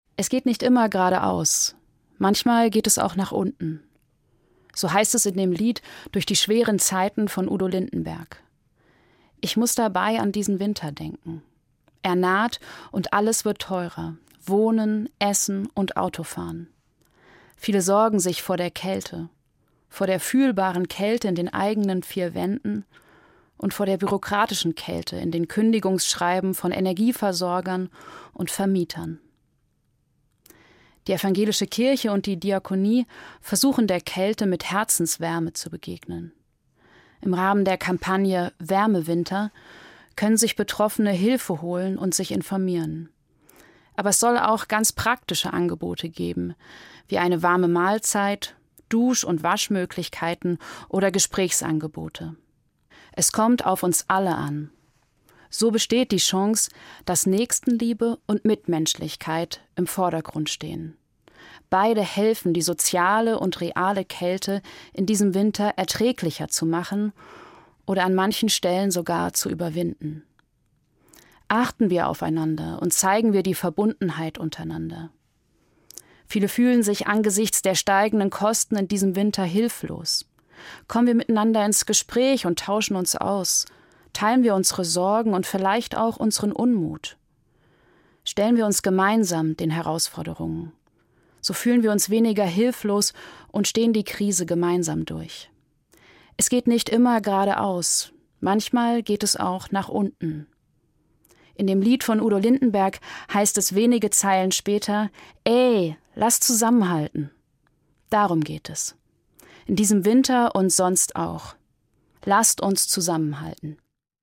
Evangelische Pfarrerin, Bad Vilbel